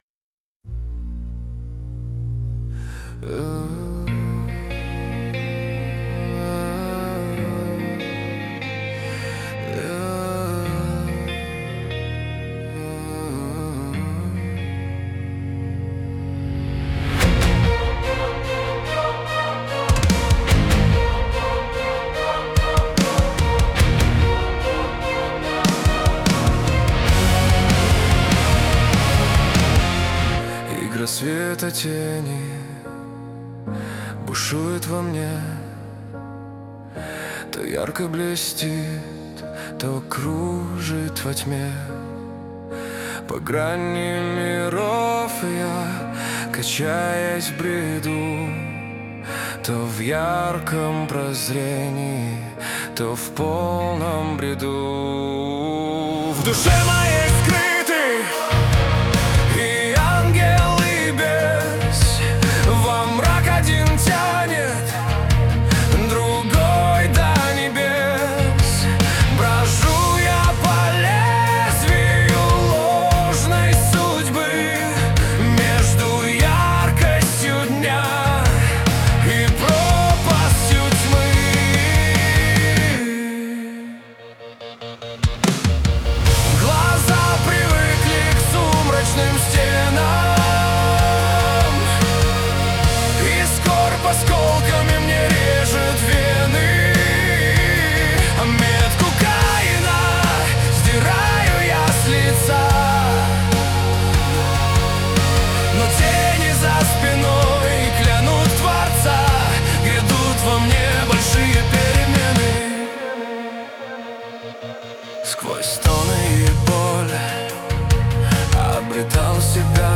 Music and vocals generated via AI tools
Музыка и вокал сгенерированы с помощью искусственного интеллекта
• Исполняет: V4.5 Fusion
• Жанр: Авторская песня